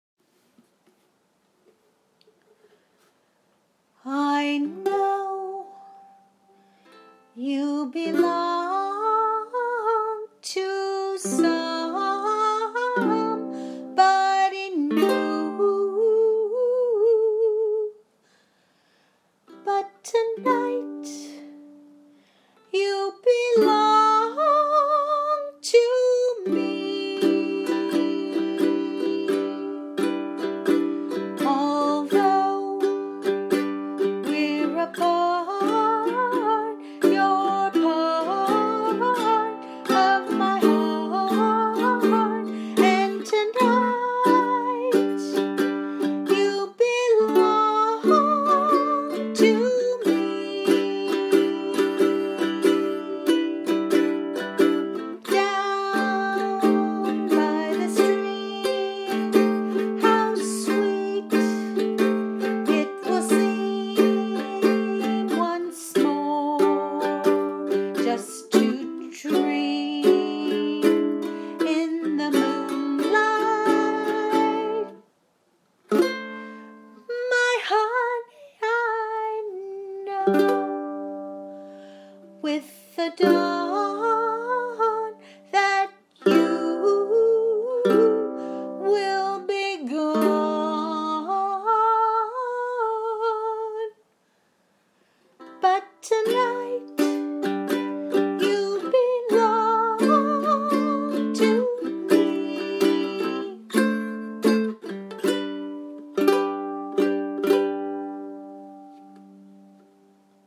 me, singing and strumming my ukulele